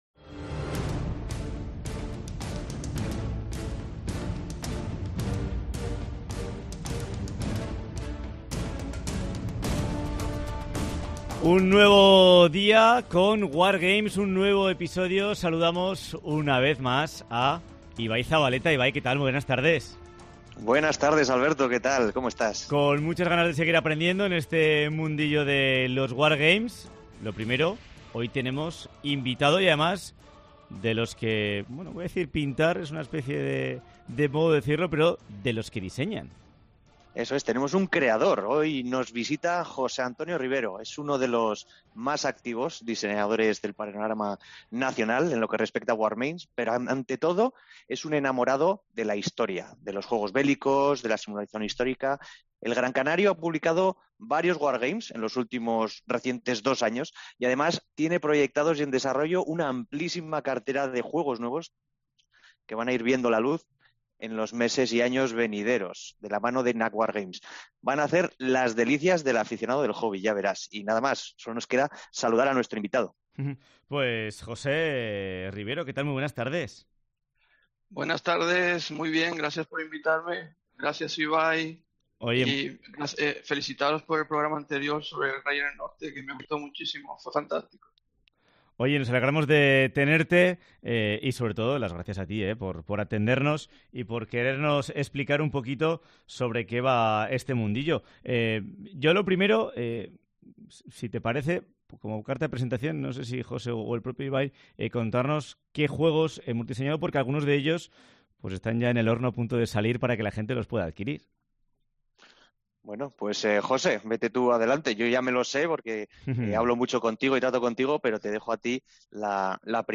VÍDEO DE LA ENTREVISTA